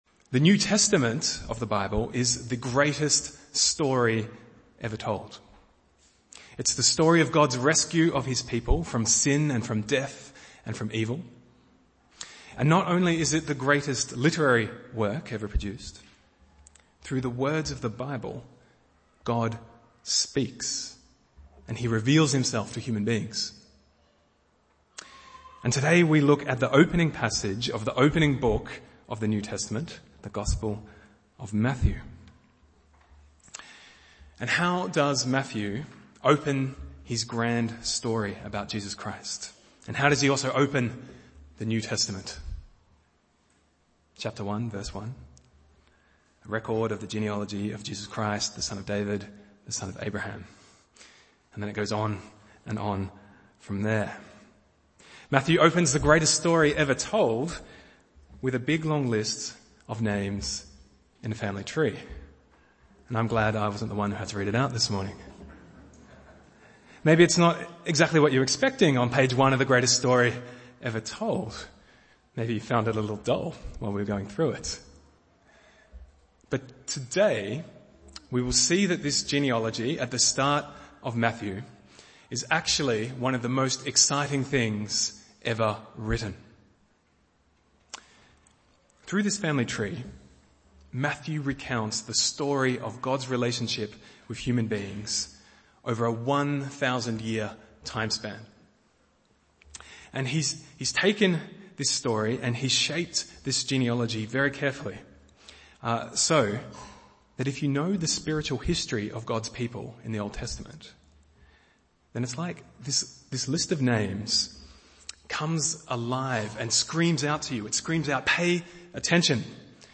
Bible Text: Matthew 1:1-17 | Preacher